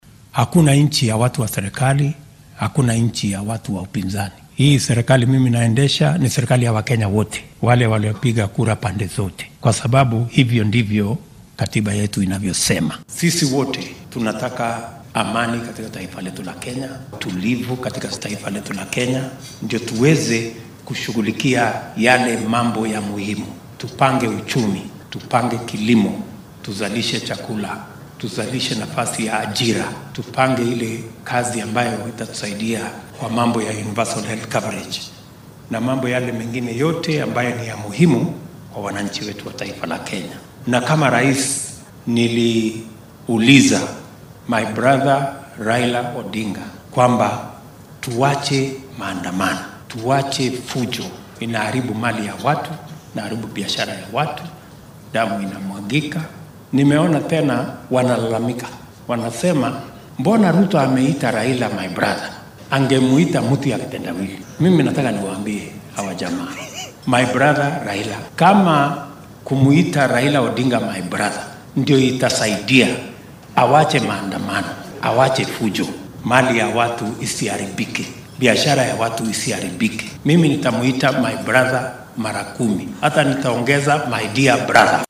Arrintan ayuu ka hadlay xilli qaar ka mid ah muslimiinta wadanka ku nool uu ku casuumay munaasabad afur ah oo shalay maqribkii lagu qabtay xarunta madaxtooyada State House ee magaalada Nairobi.